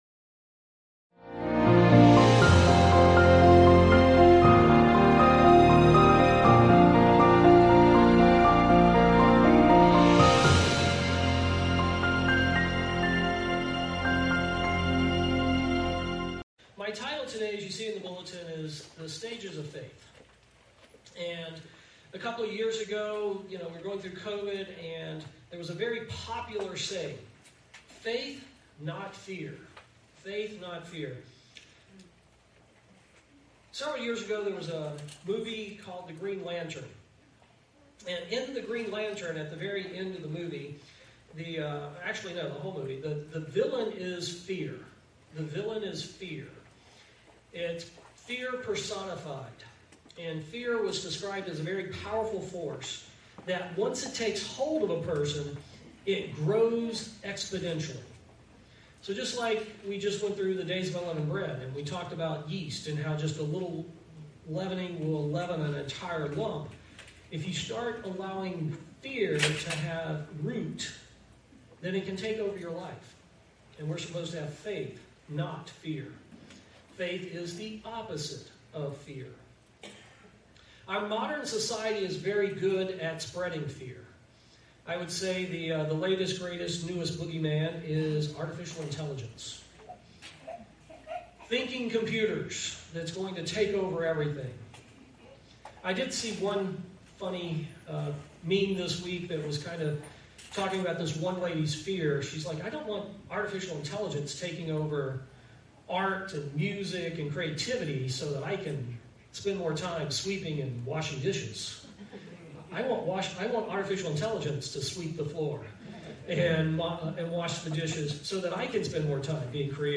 In this sermon we examine Faith and the different stages of Faith that Christians develop as they grow in God’s grace and knowledge. Join us as we discover how Faith brings us closer to God.